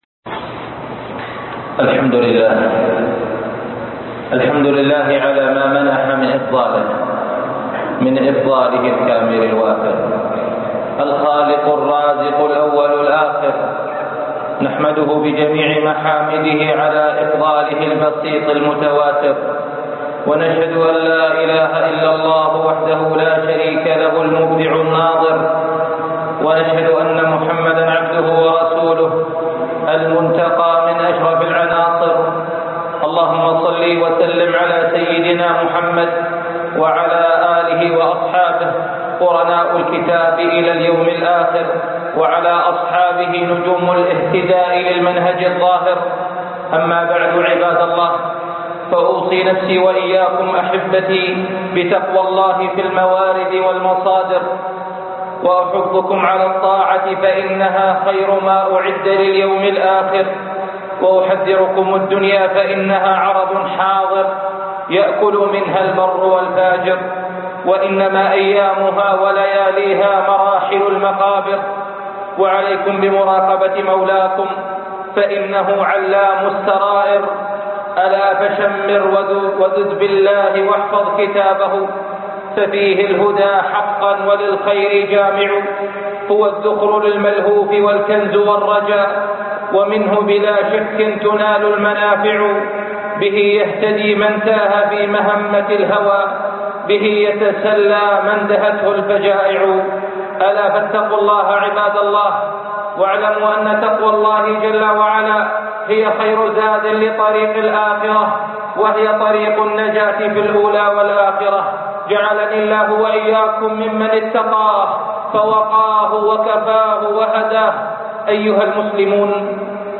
الذوق - خطب الجمعة